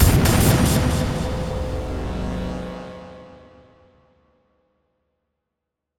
Impact 19.wav